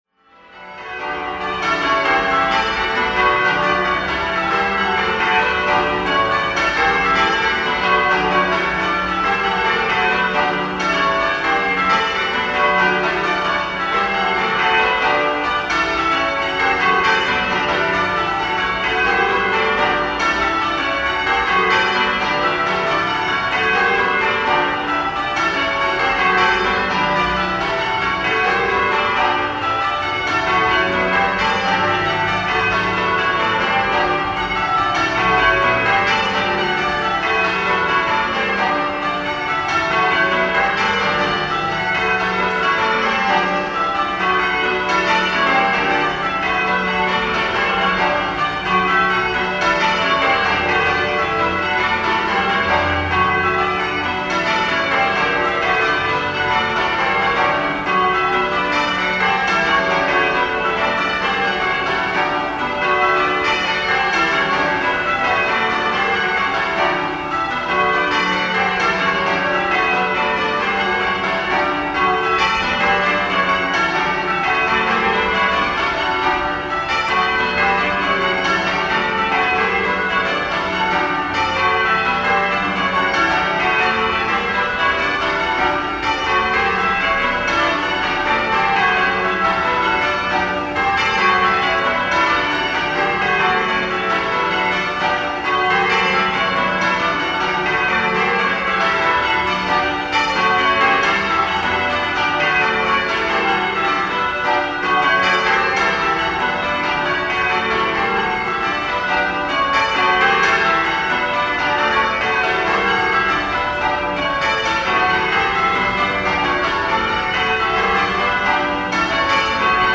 10 bells 19-3-15 in D Excellent going order
Extract from ASCY peal of Stedman Caters, 16/11/2024
StowmarketStedCaters02.mp3